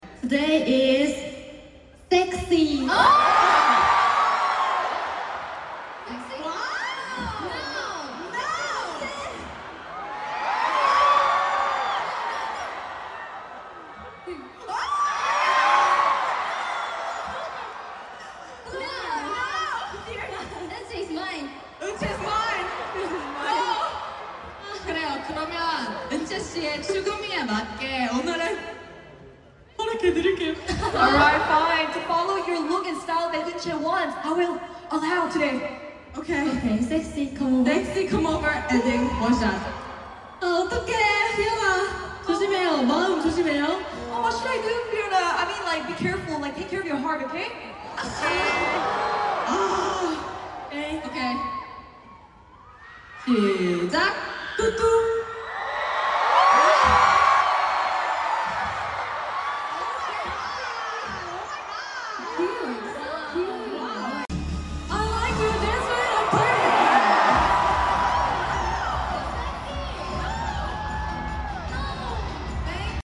TOUR IN SINGAPORE
kpop